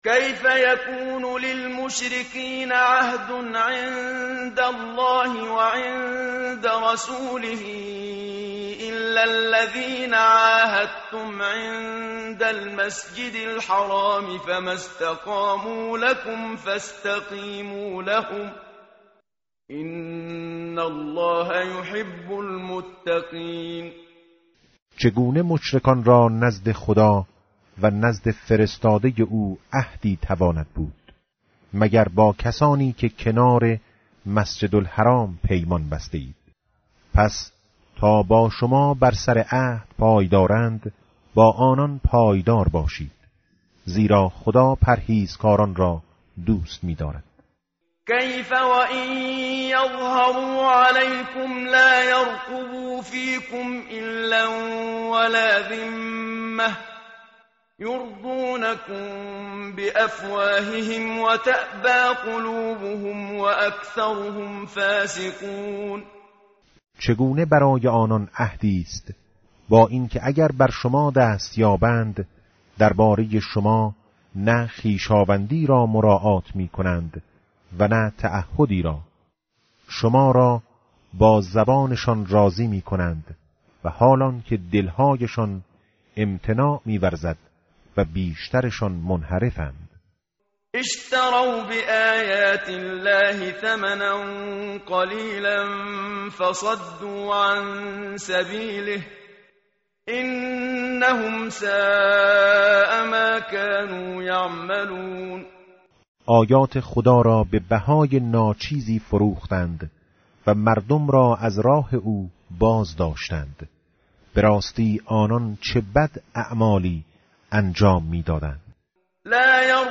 tartil_menshavi va tarjome_Page_188.mp3